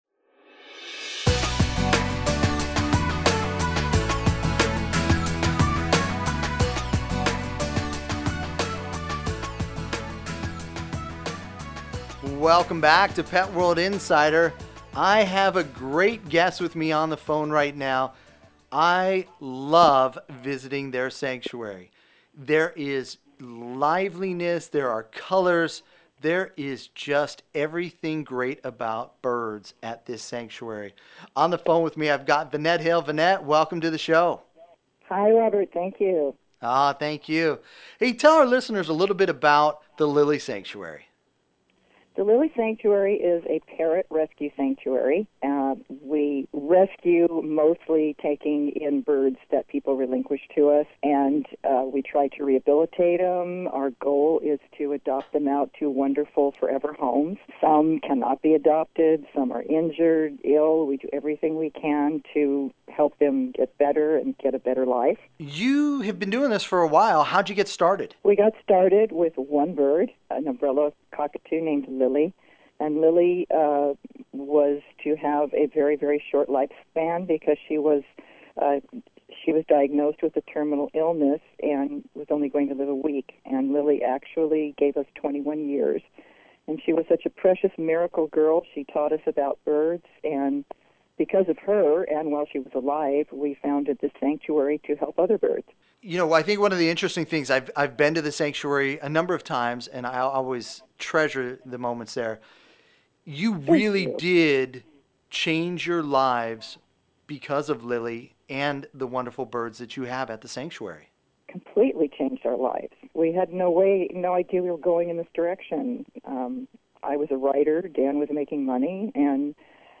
Enjoy our daily Radio Segments in case we haven’t been added to a radio station in your area yet.